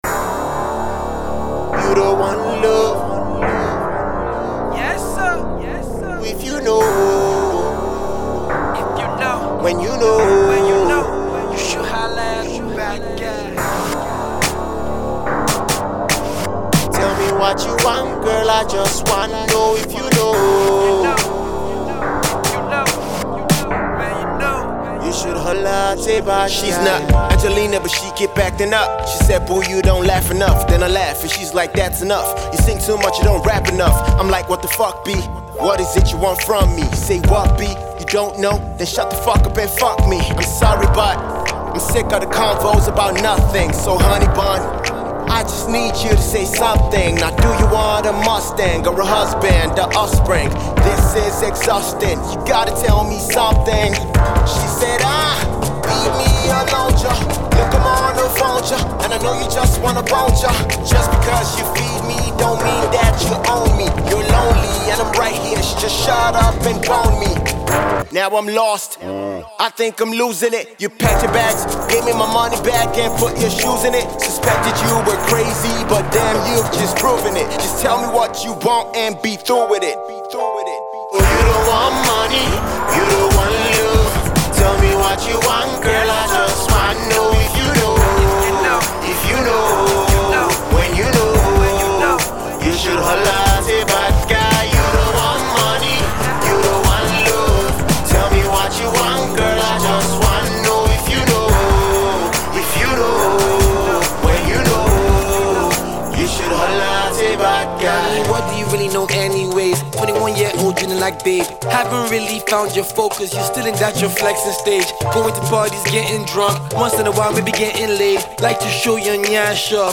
AudioRap